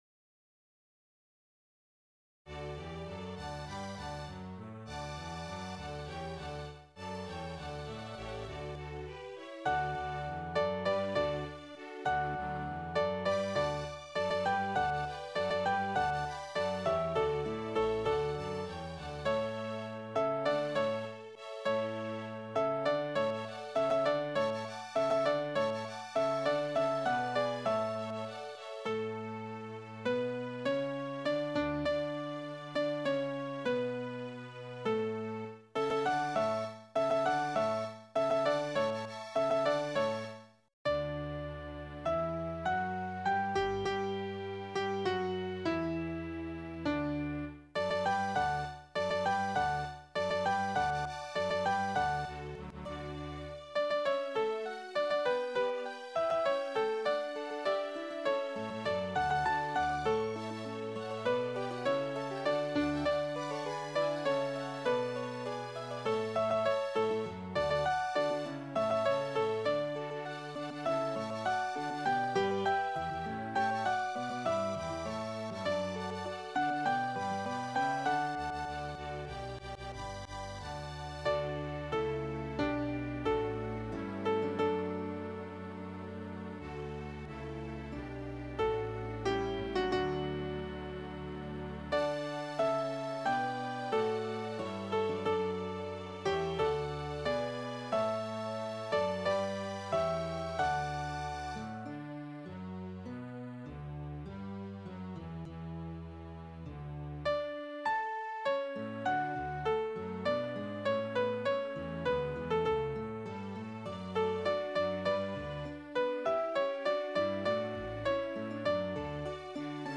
Altto